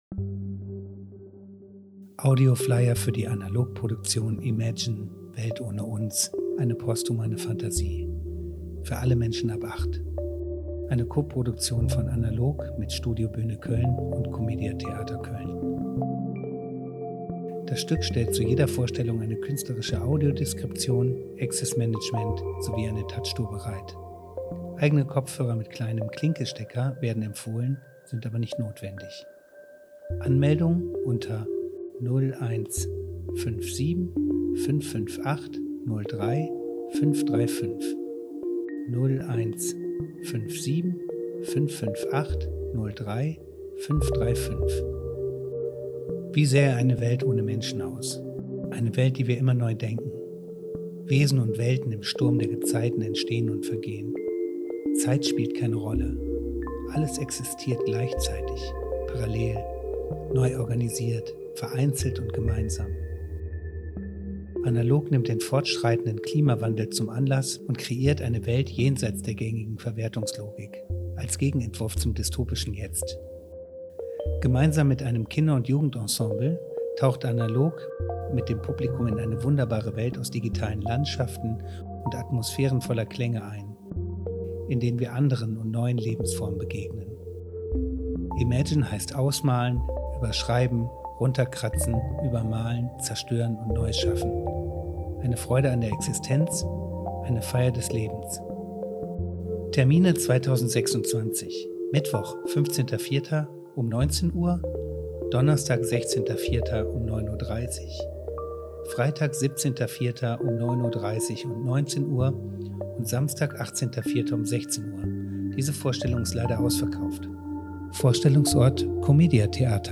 Audioflyer